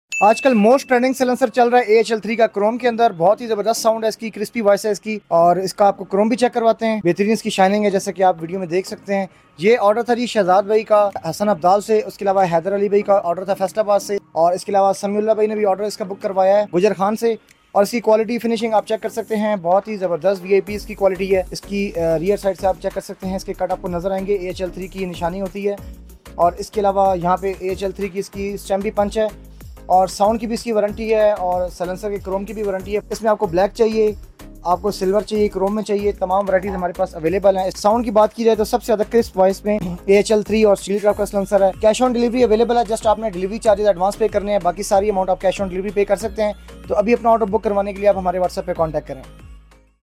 AHL 3 Chrome Silencor CG125 | sound effects free download